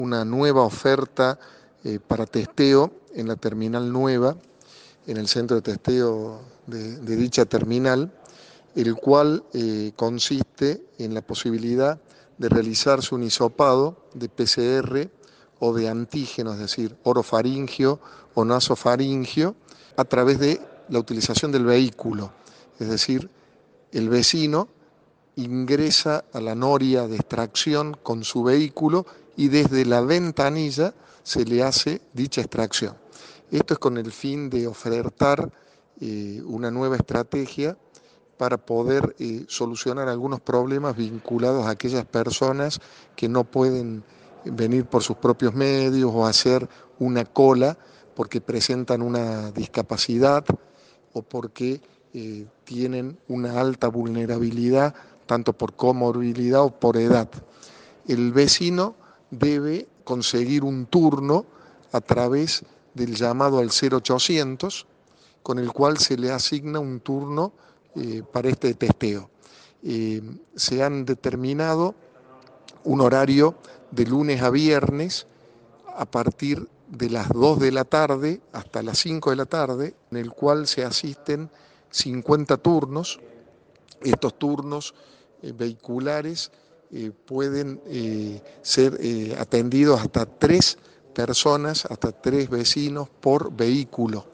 Audio: El titular del COE, Juan Ledesma, explicó cómo se realizan los testeos dentro de los vehículos.